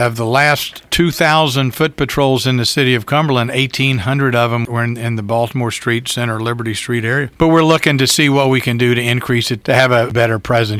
Mayor Ray Morriss tells WCBC the police department’s foot patrols in the area have increased over the past year, along with arrests, but more can be done…